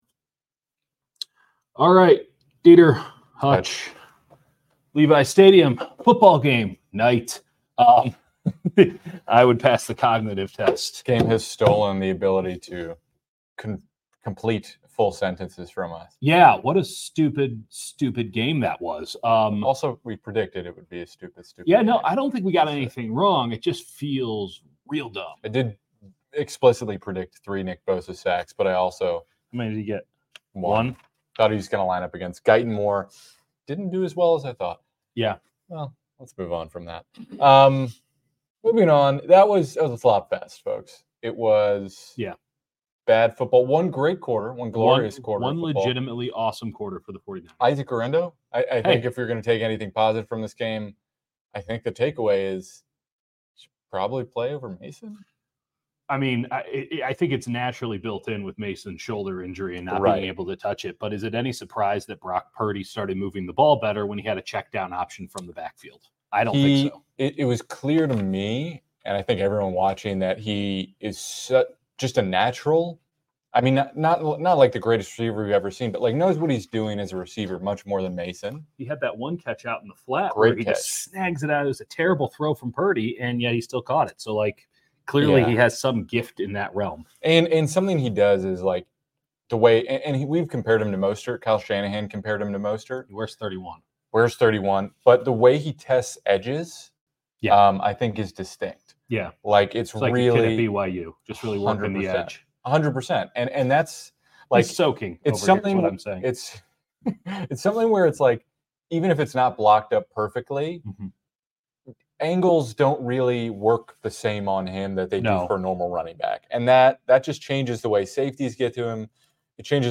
49ers beat Cowboys LIVE reaction | Making sense of the messy win